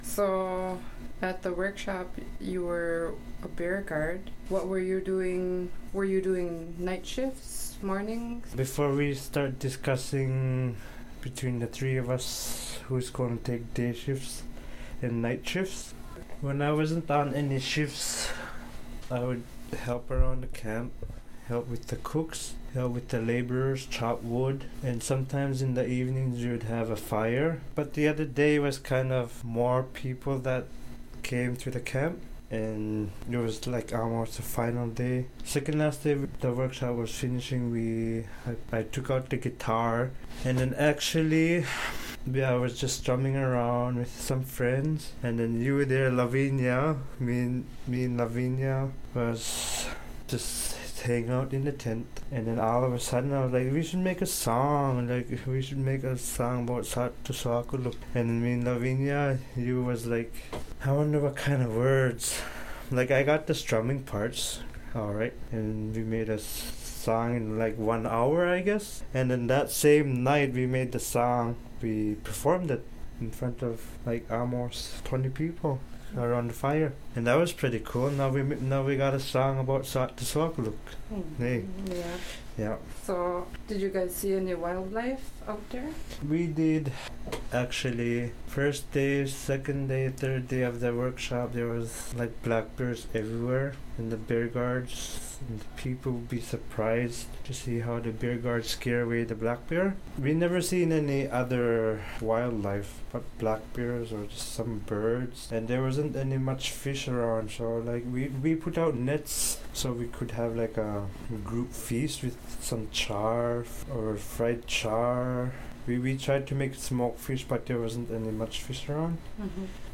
OKâlaKatiget Radio and the TV department had the opportunity to attend to the workshop to conduct some interviews.